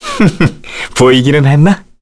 Roi-Vox_Victory_kr.wav